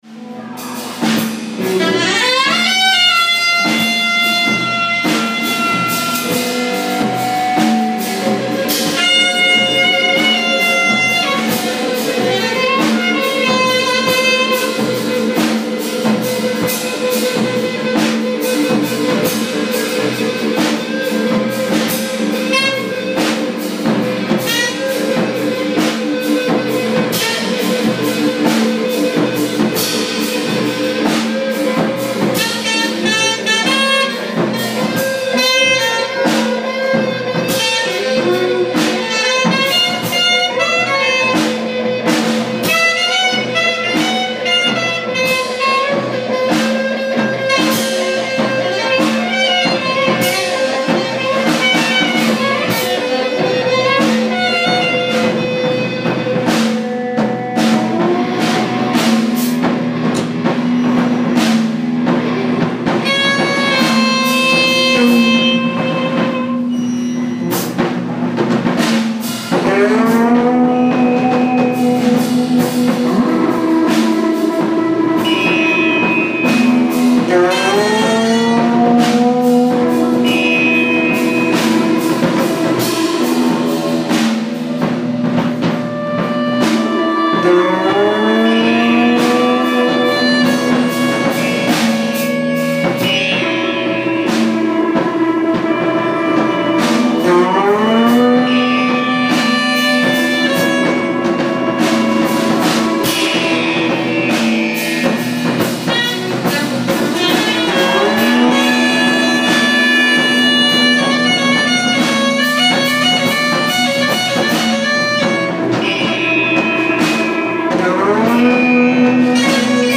ALL MUSIC IS IMPROVISED ON SITE